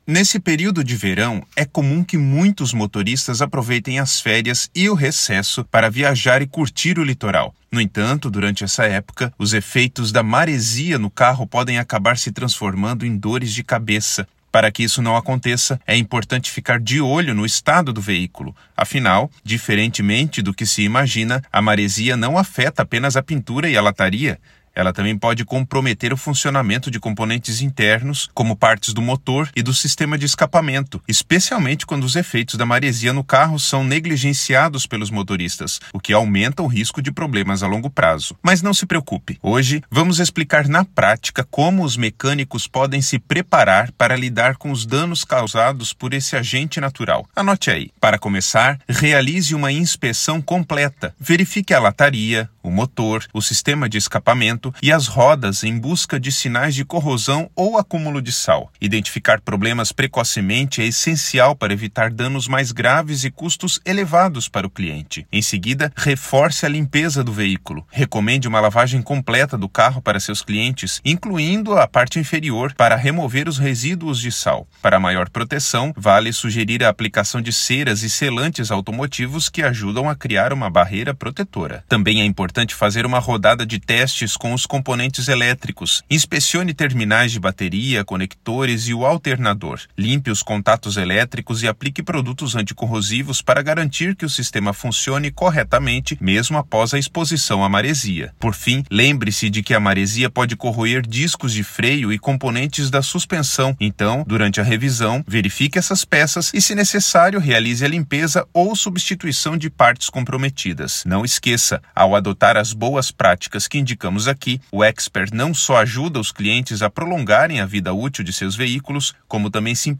Narracao-01-efeitos-da-maresia-no-carro.mp3